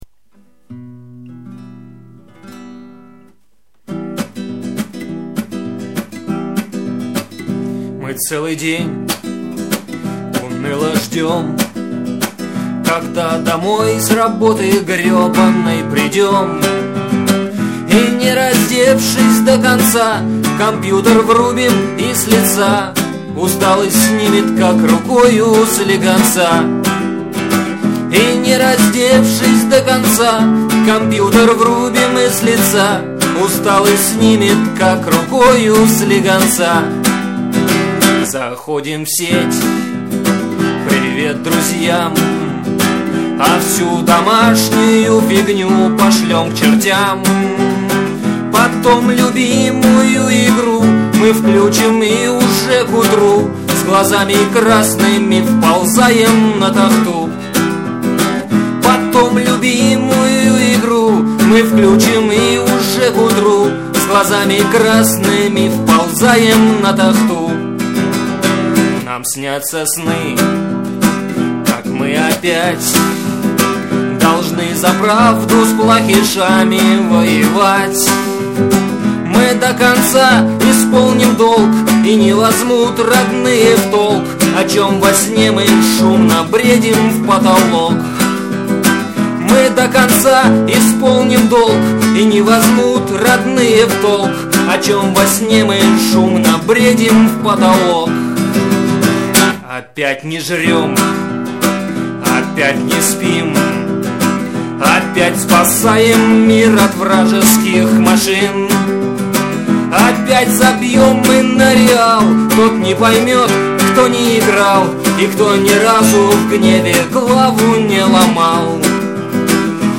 • Жанр: Комедия